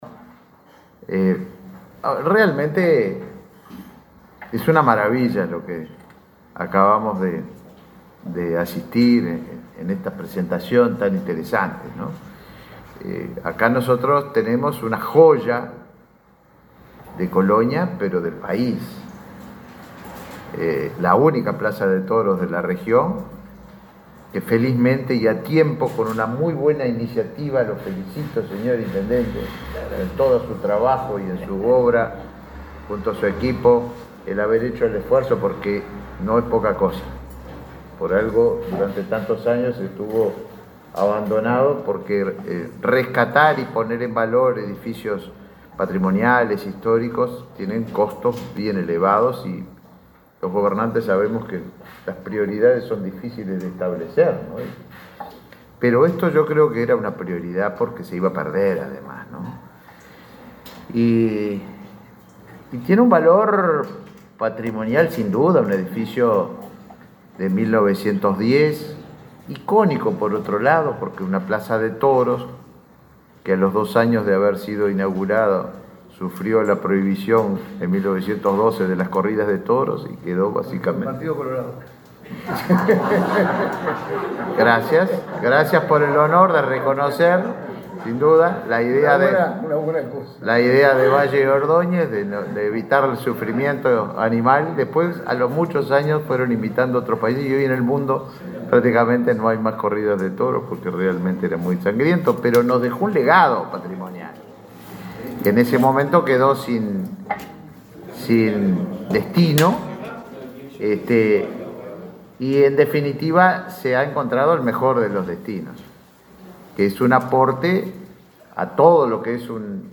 Palabras del ministro de Turismo, Tabaré Viera
Este lunes 18, en Montevideo, el ministro de Turismo, Tabaré Viera, participó en la presentación de la agenda de espectáculos de la plaza de toros de